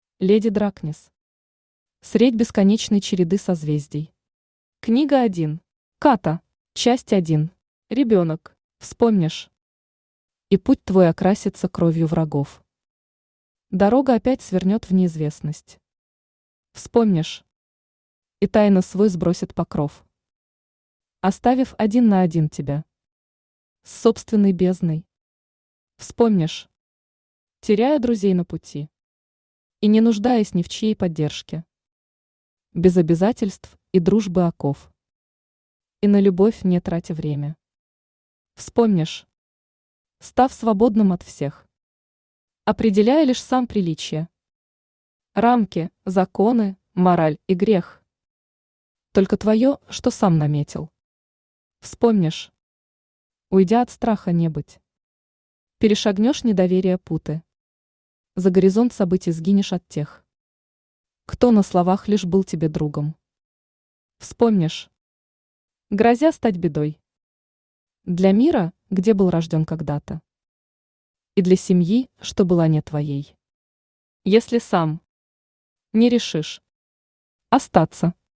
Ката Автор Леди Дракнесс Читает аудиокнигу Авточтец ЛитРес.